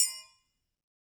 Triangle6-HitM_v2_rr1_Sum.wav